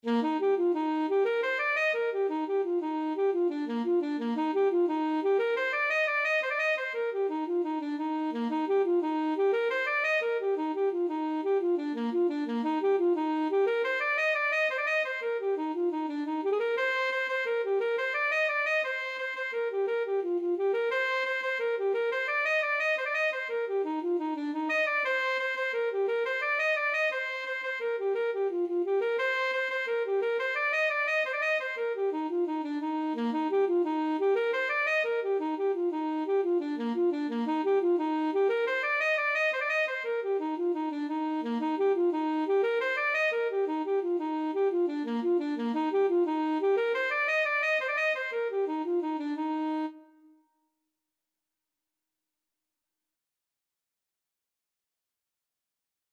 Alto Saxophone
6/8 (View more 6/8 Music)
Bb4-Eb6
With energy .=c.116
Irish